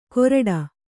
♪ koraḍa